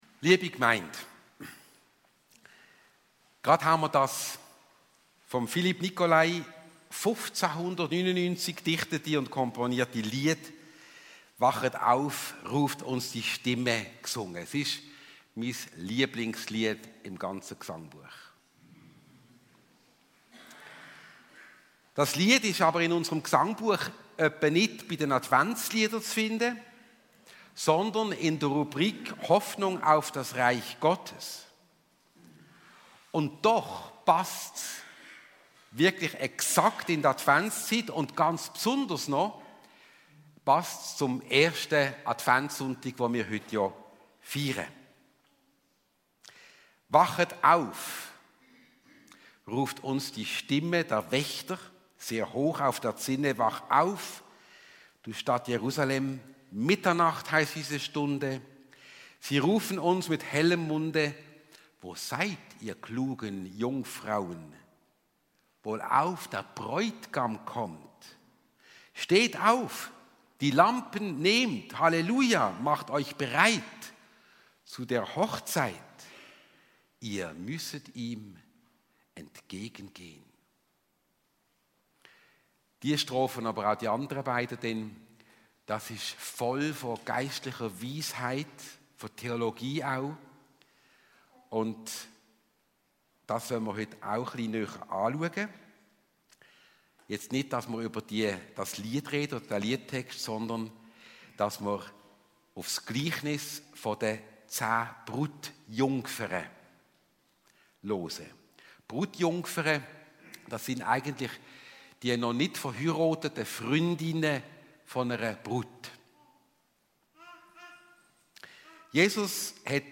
Predigt zum 1. Advent zum Thema «Wachet auf» .